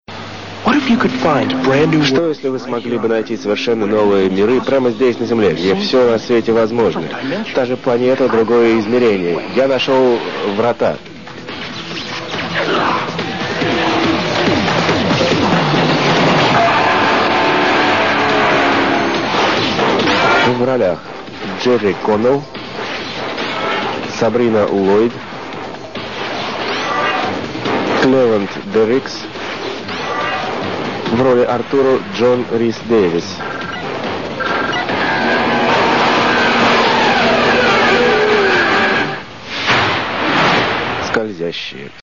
In der 1. Staffel wird der russische Text von einem Übersetzter über die englische Version gesprochen. So hört man auch noch die Orginalstimme von Jerry O'Connell.